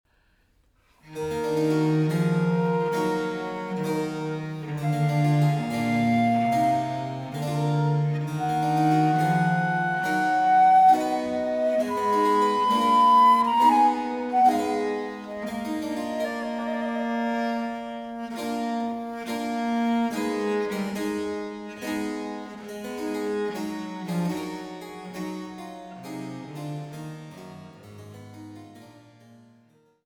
Traversflöte